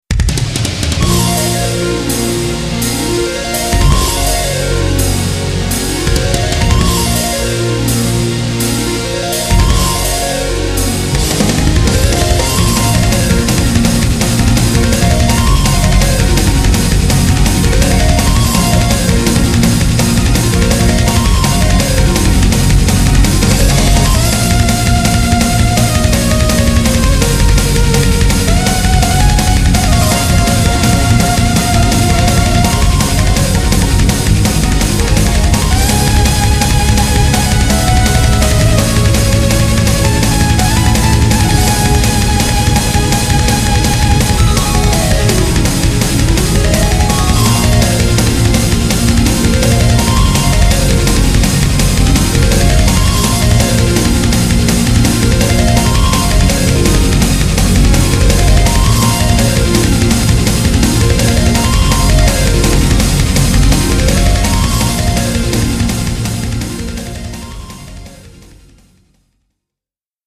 mp3 最初のアルペジオをもっと綺麗にしたい…
音源モジュール YAMAHA MU2000